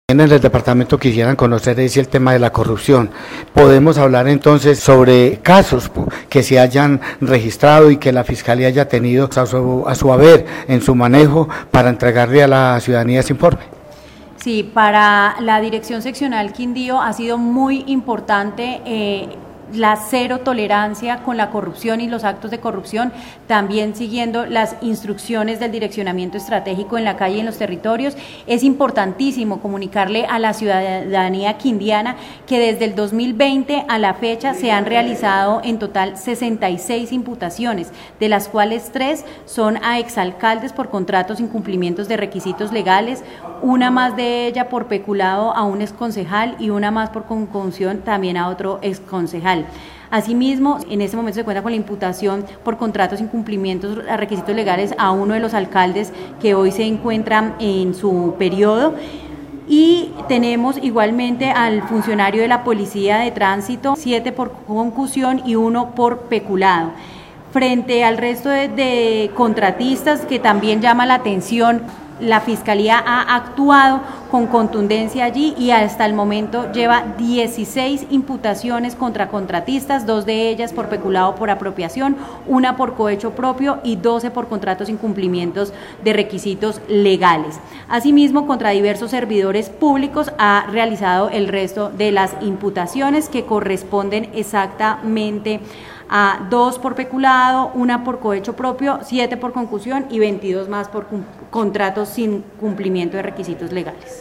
El Portal Web Periodismo Investigativo dialogó con la directora seccional de fiscalías del Quindío Dra. Leonor Merchán Lopera, quien explicó que ‘’el trabajo de la mano con la dirección especializada contra la corrupción ha permitido dar estos resultados y los que vienen’’